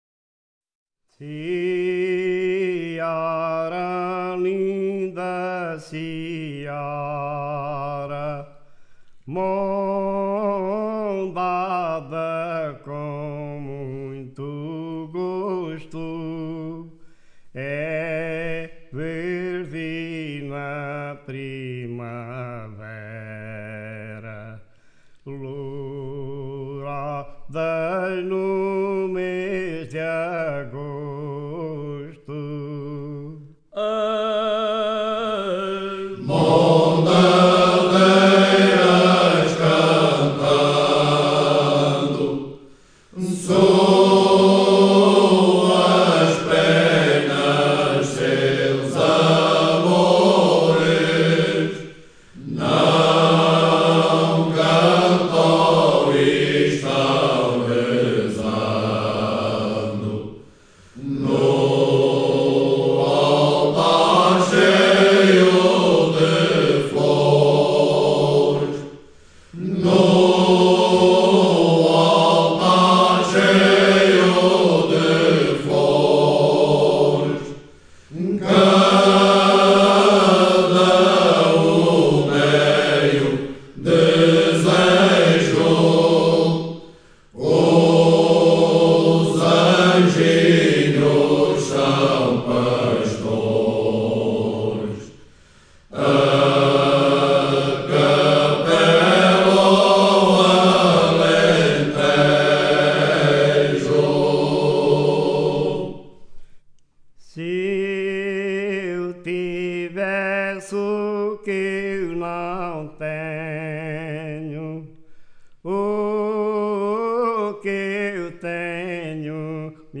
inetmd-fcsh-cnt-audio-as_mondadeiras_cantando.mp3